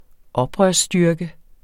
Udtale [ ˈʌbʁɶɐ̯s- ]